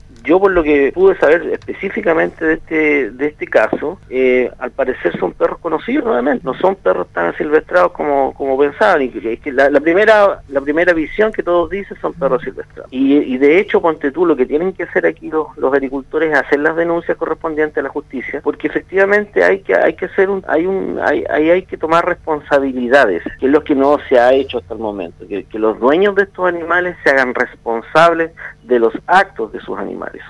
Sobre esto conversó con Radio Sago, el director Regional del SAG, Andrés Duval quien dijo que están esperando una ley de tenencia responsable que se está discutiendo para darle la relevancia que merece este tema pero que, sin embargo aún no ha salido del Parlamento.